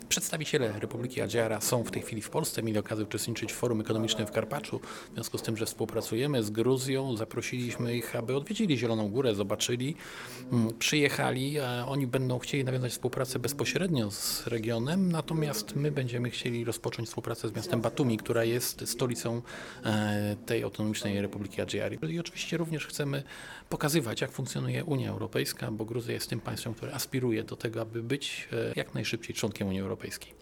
Mówi prezydent Zielonej Góry, Janusz Kubicki: